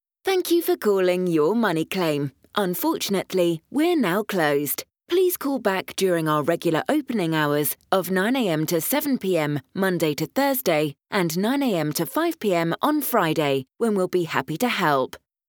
I can arrange voice over recordings for music on hold and IVR projects for your business.
I only use industry standard voice artists for my voice overs to create a professional finished product.
IVR Demo 1